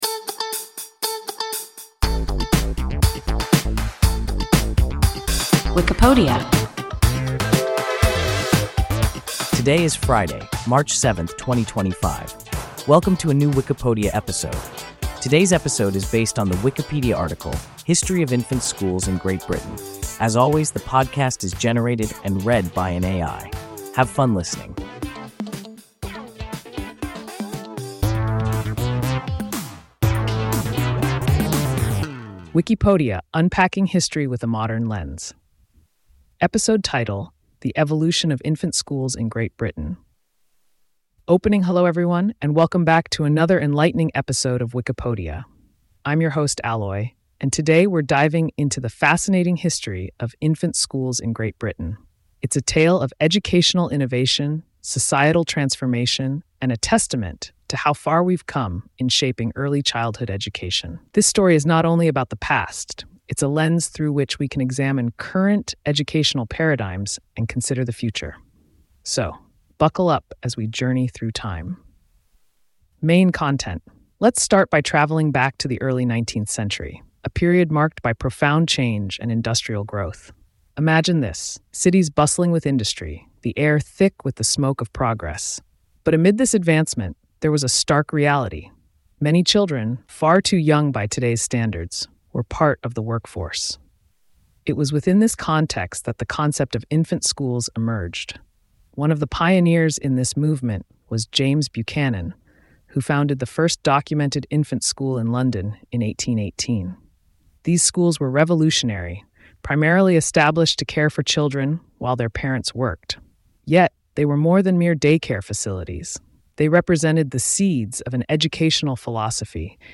History of infant schools in Great Britain – WIKIPODIA – ein KI Podcast